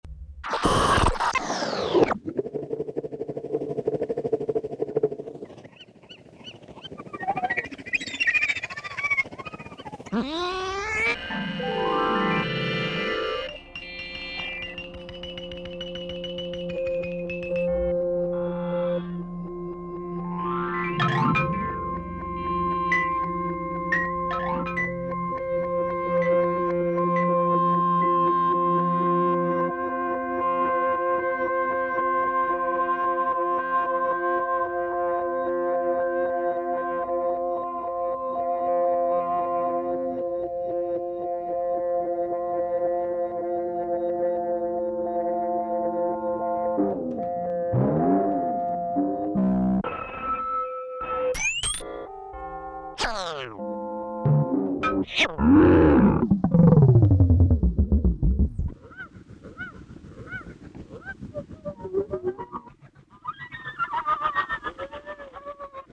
musique concrète